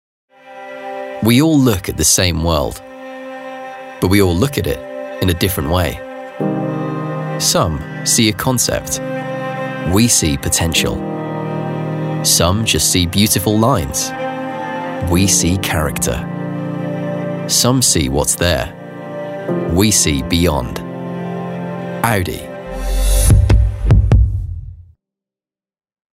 Versatile professional voiceover and actor with an RP/neutral English accent and an engaging, friendly and clear voice. Excels at natural, conversational corporate reads and character work. Broadcast-quality studio with rapid turnaround of finished audio available.